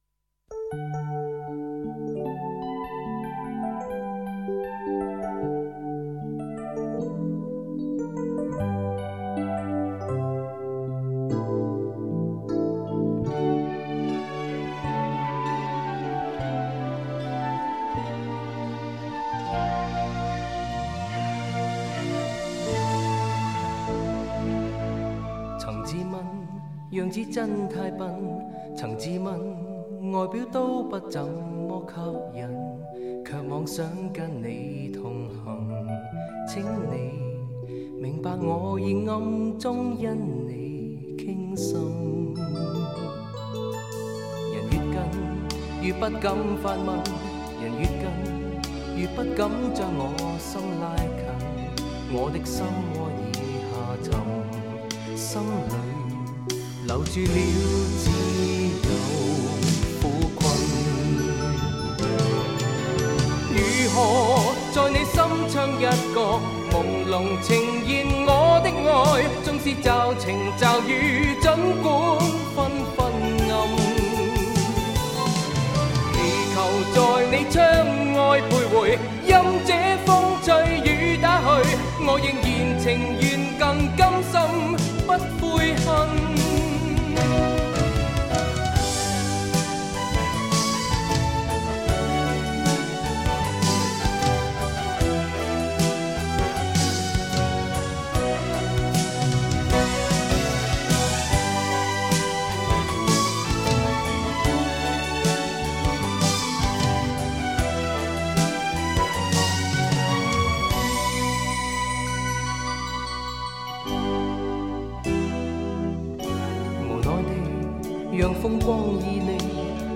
乐队的风格：舞曲（在香港比较少见）